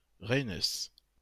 Reynès (French pronunciation: [ʁɛnɛs]